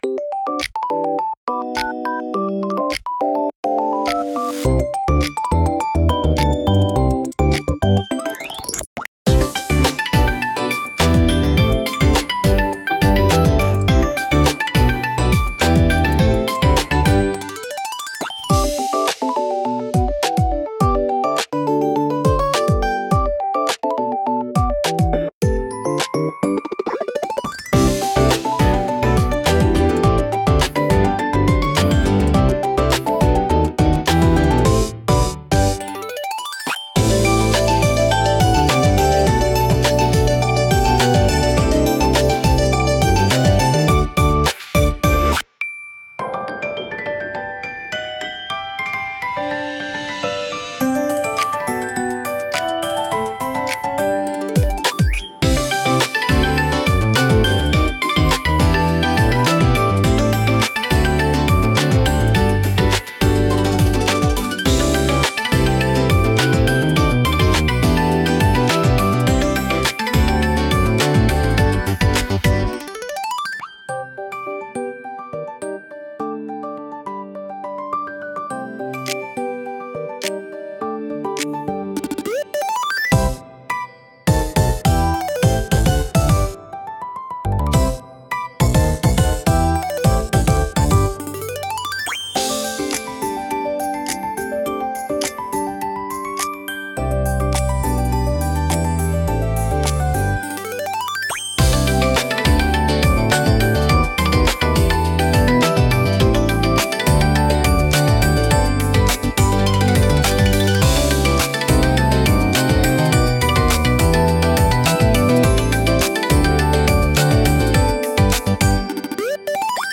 キラキラ駆け抜ける、ゆめかわポップBGM